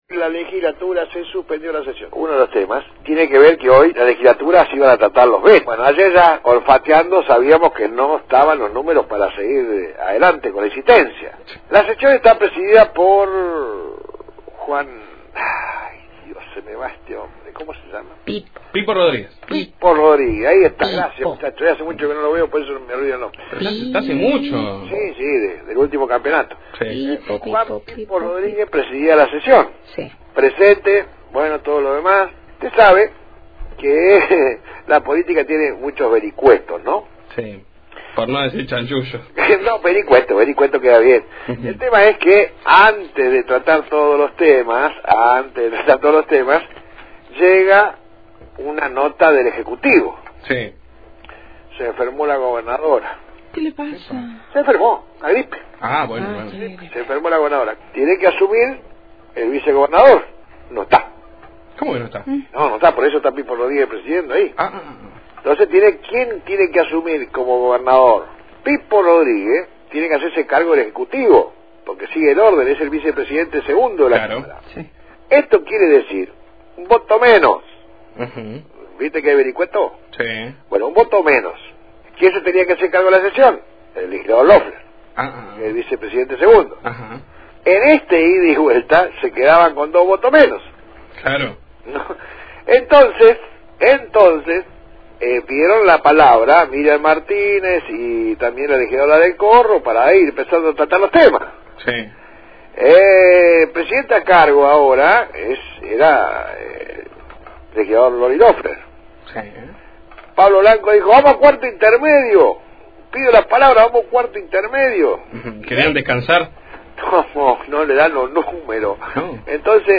Audio: Informe